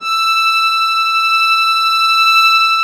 MUSETTESW.19.wav